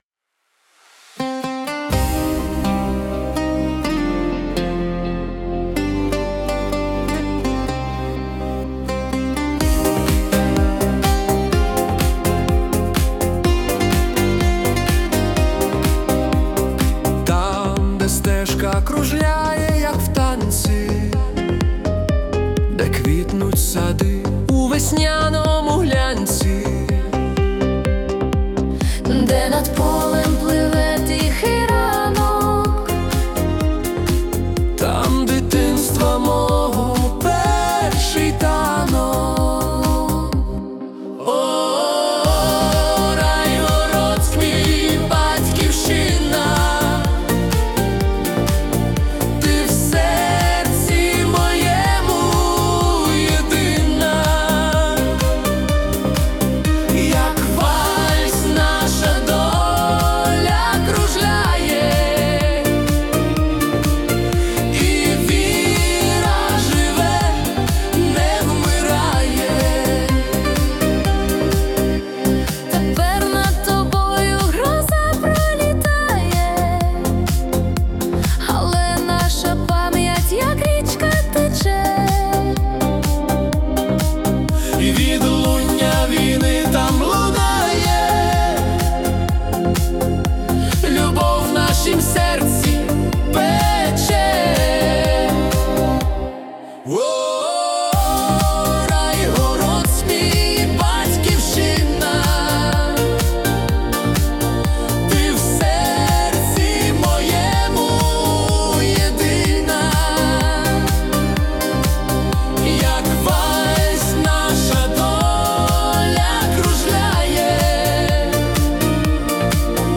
🎵 Жанр: Italo Disco (Nostalgic)
Ритм диско додає цій вірі енергії та життєствердності.